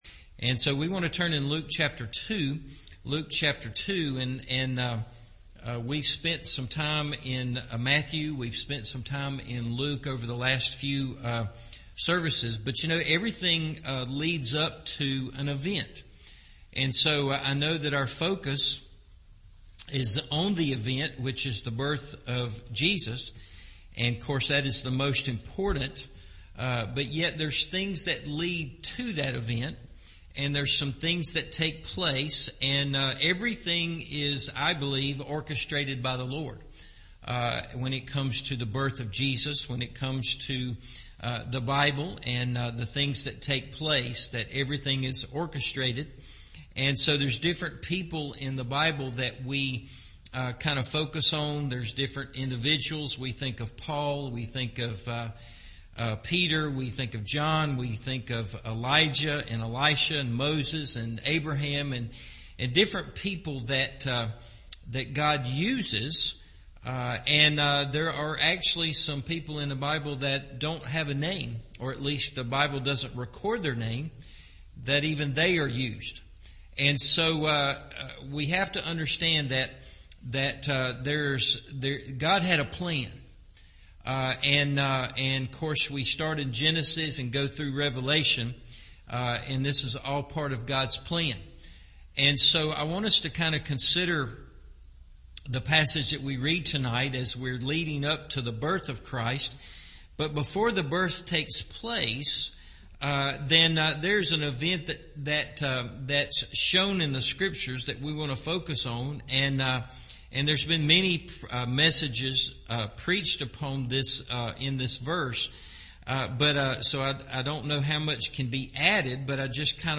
Making Room For Jesus – Evening Service